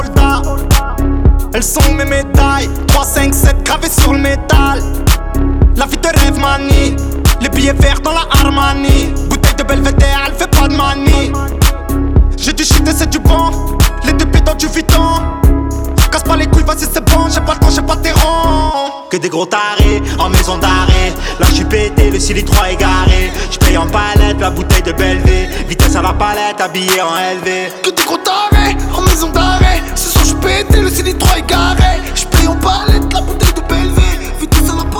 Hip-Hop Rap French Pop
Жанр: Хип-Хоп / Рэп / Поп музыка